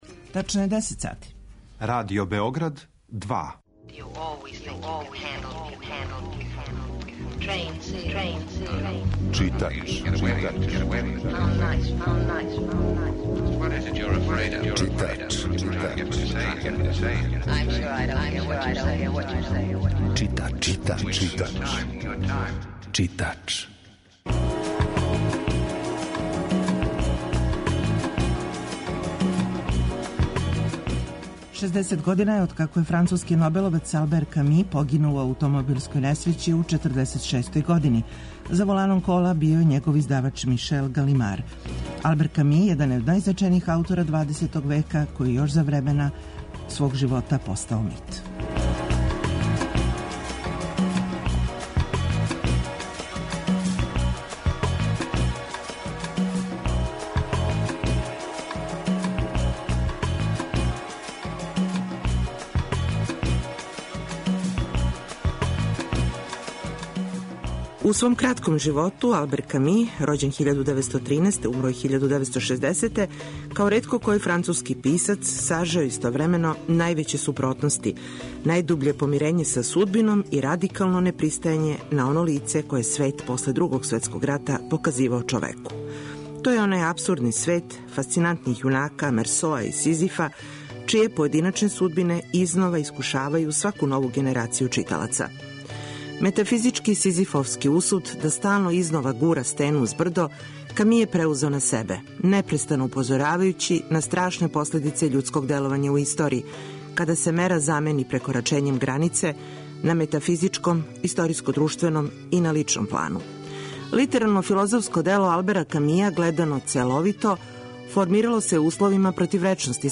Емисија је колажног типа